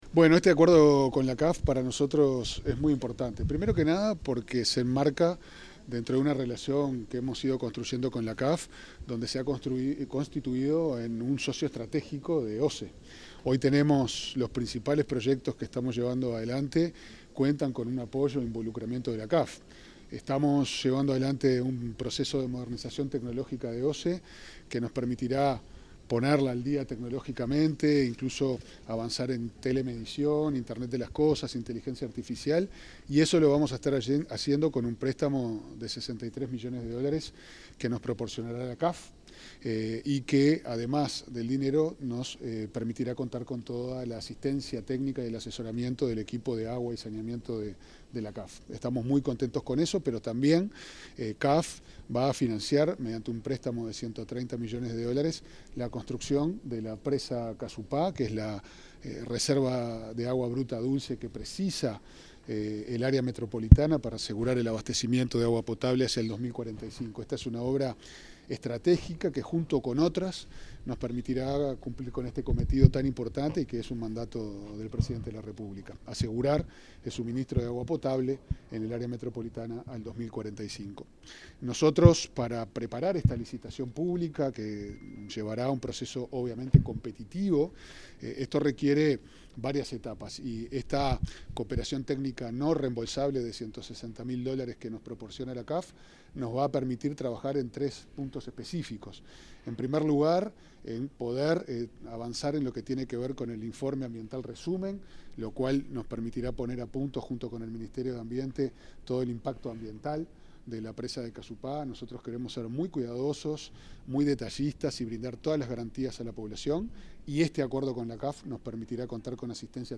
Declaraciones del presidente de OSE, Pablo Ferreri
El presidente de OSE, Pablo Ferreri, detalló, en declaraciones a la prensa, el objetivo y el alcance de un acuerdo de cooperación técnica firmado con